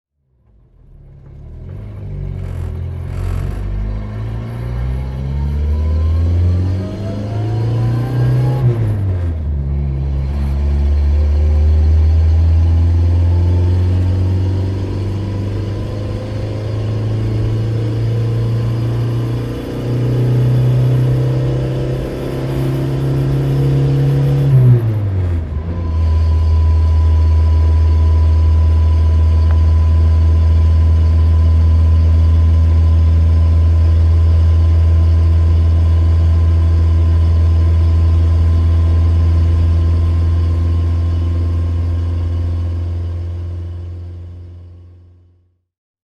Motorsounds und Tonaufnahmen zu Renault Fahrzeugen (zufällige Auswahl)
Renault R8 (1964) - Innengeräusch
Renault_R8_(1964)_-_Innengeraeusch.mp3